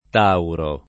Tauro [ t # uro ]